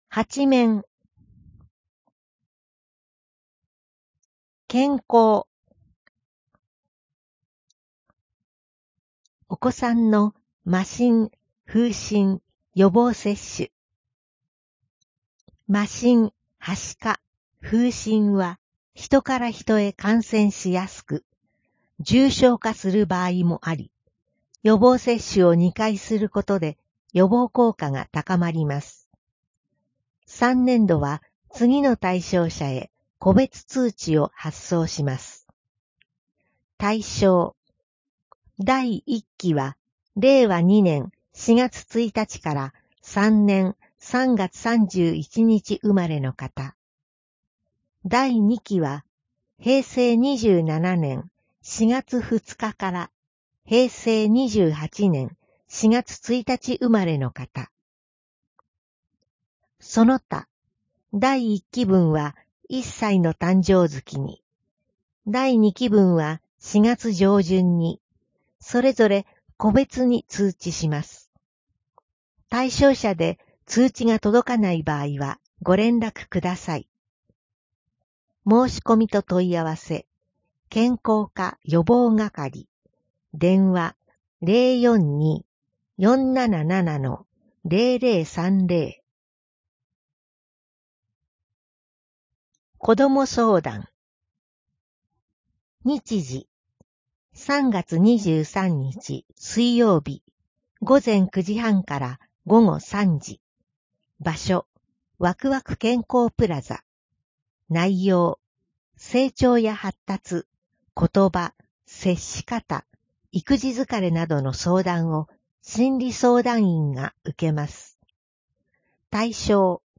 声の広報（令和4年3月15日号）